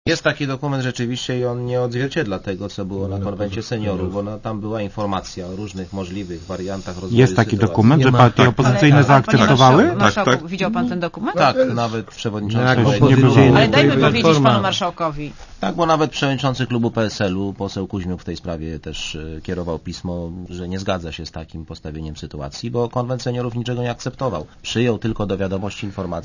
Czy kompromis w sprawie unijnej konstytucji jest realny i czy jest goda Sejmu na „zmiękczenie” polskiego stanowiska? O tym w Radiu Zet rozmawiali goście programu 7. Dzień Tygodnia.